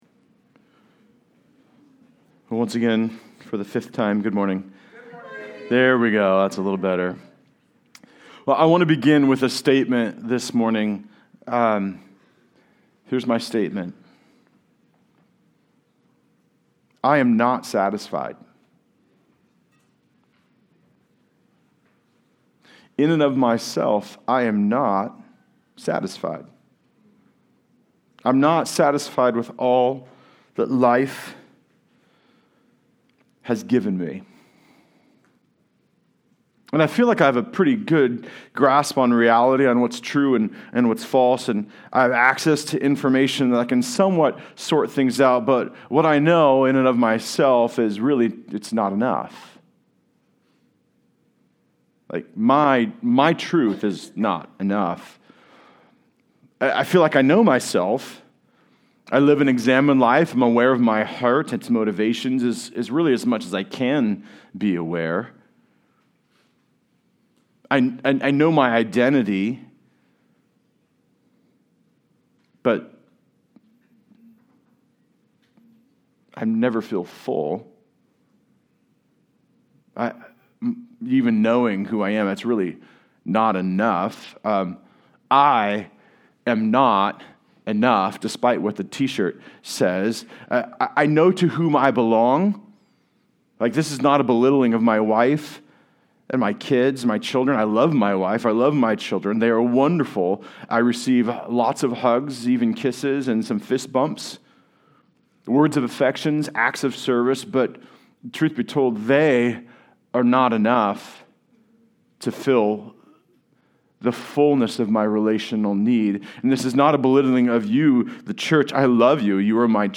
23-40 Service Type: Sunday Service Related « The King Will Reign In Our Midst 1 Corinthians 9